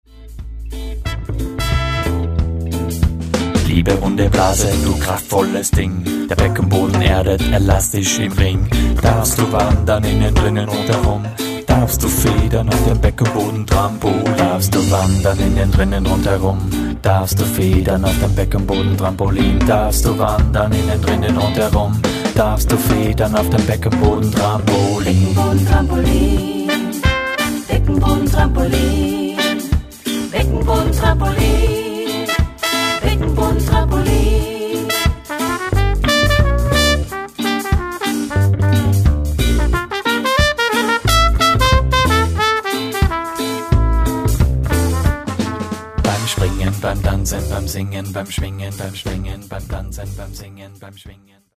Recorded at: Dschungelstudios Vienna, Austria